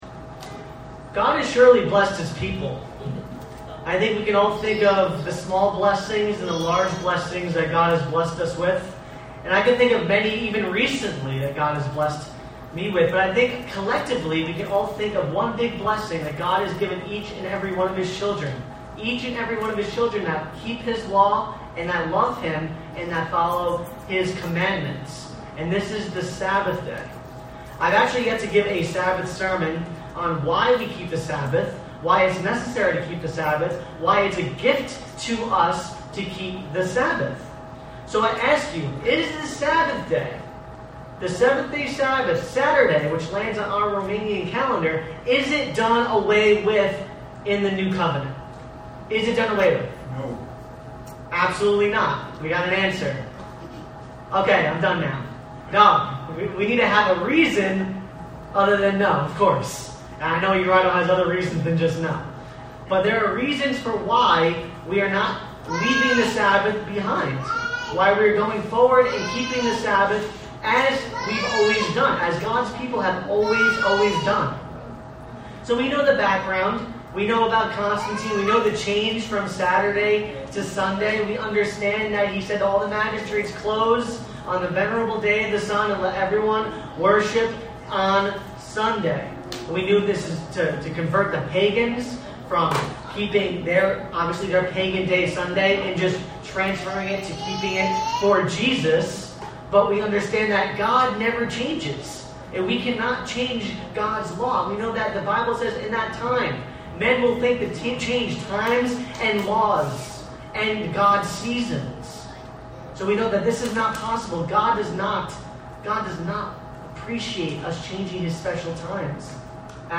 God set aside His Sabbath Day as an important period of time each week for His people to come together to worship Him. This sermon goes through a handful of important Sabbath related apologetics.